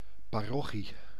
Ääntäminen
Tuntematon aksentti: IPA: /pa.ʁwas/